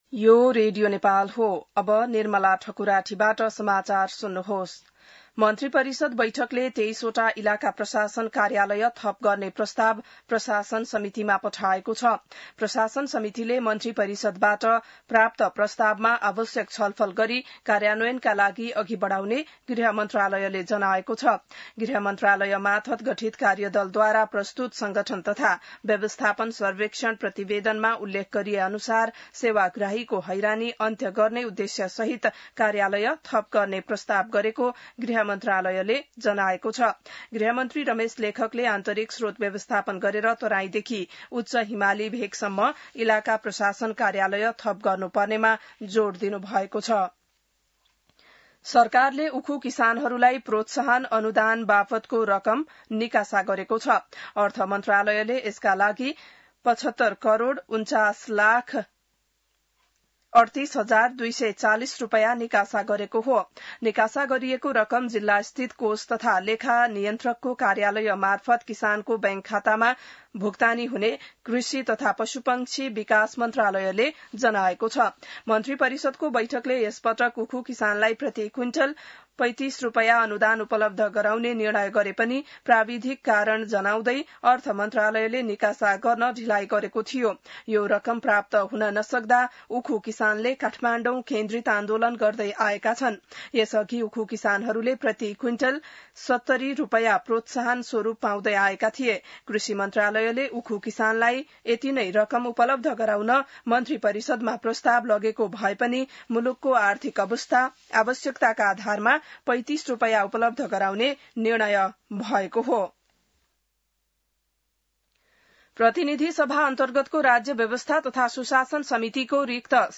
बिहान ६ बजेको नेपाली समाचार : ११ भदौ , २०८२